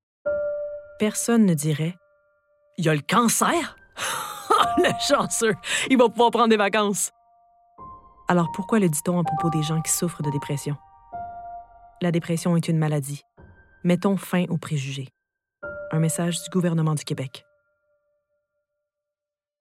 Timbre Grave
Santé mentale - Empathique - Sérieuse - Québécois naturel /
Pub sociétale + Annonceuse - Fictif 2022 00:20 364 Ko